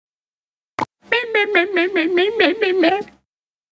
Memes
Crying Goblin Emote